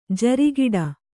♪ jari giḍa